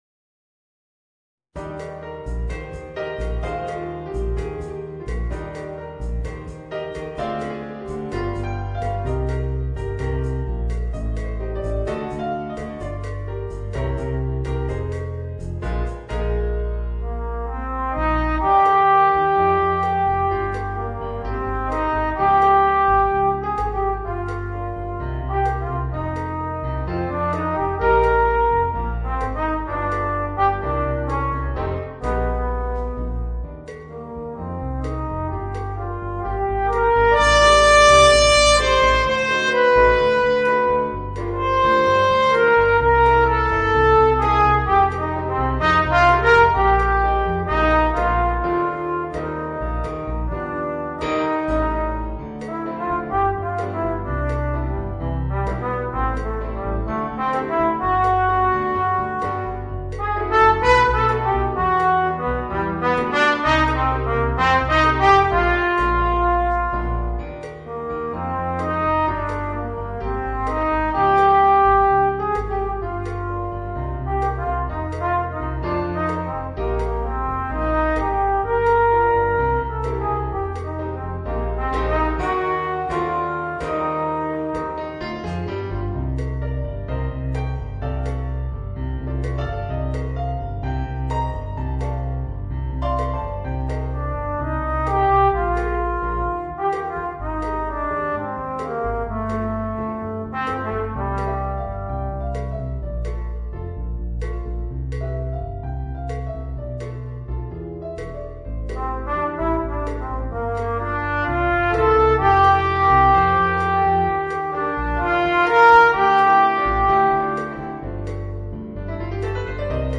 Trombone & Piano (Bass Guitar & Drums optional)